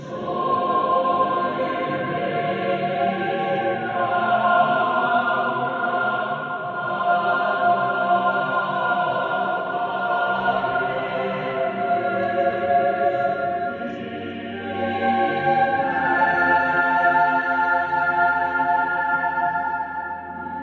On Sunday we went to Hallgremskirja for worship in Icelandic. The acoustics made the 15-member choir sound like a much bigger choir.
Play a 20-second clip of the choir.